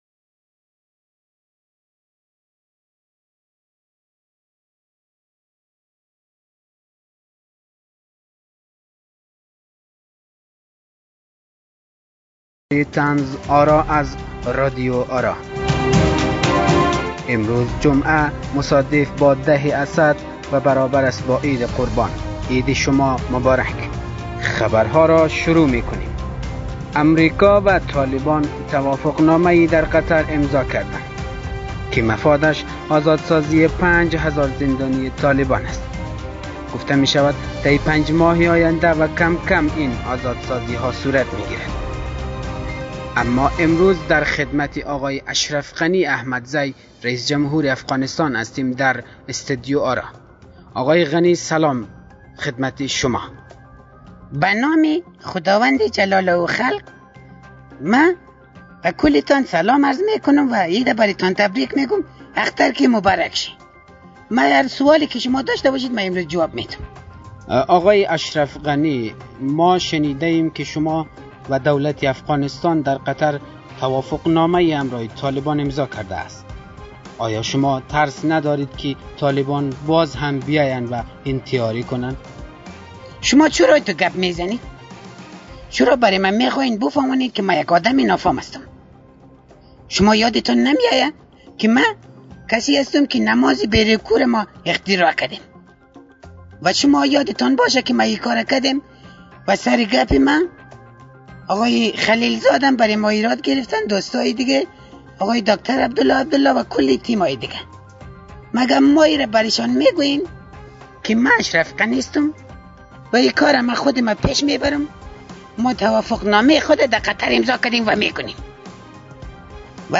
پادکست «طنزآرا» با اجرای تیمی از استنداپ کمیدین ها و طنزپرداز های خوب افغانستانی تهیه می شود.